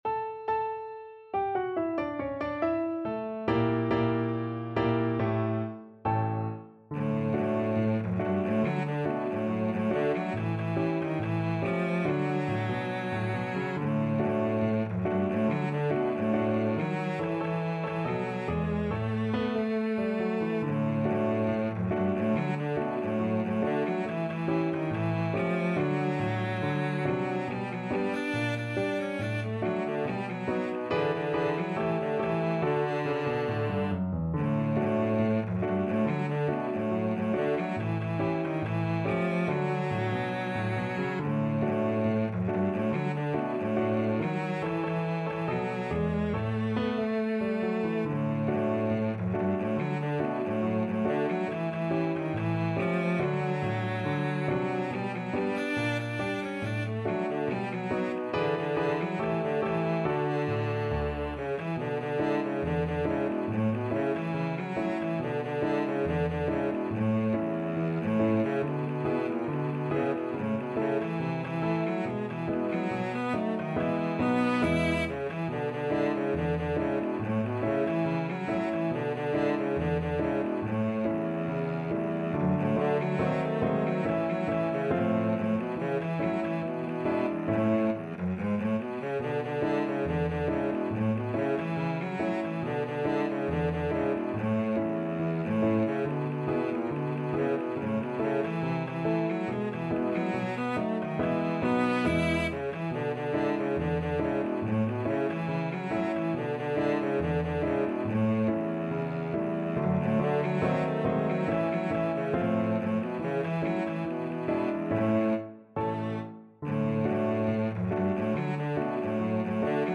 Cello
2/4 (View more 2/4 Music)
Not fast Not fast. = 70
Jazz (View more Jazz Cello Music)